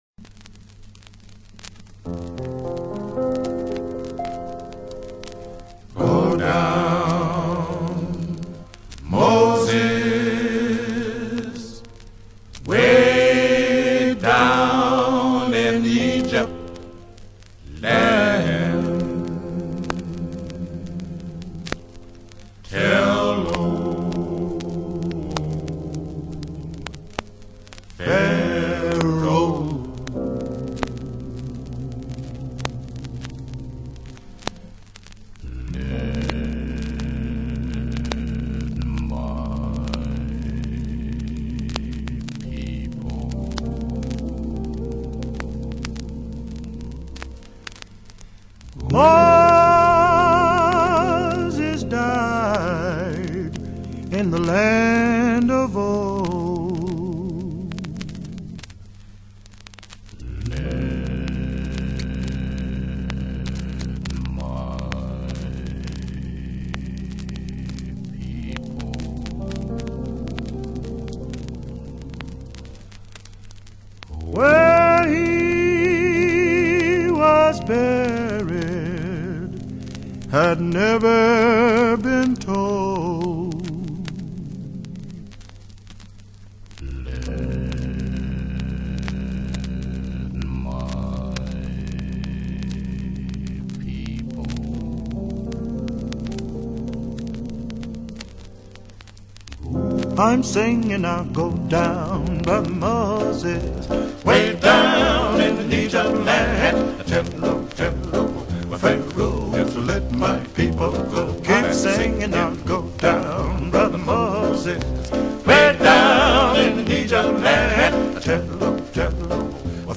Negro Spiritual :